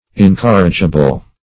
Incorrigible \In*cor"ri*gi*ble\, n.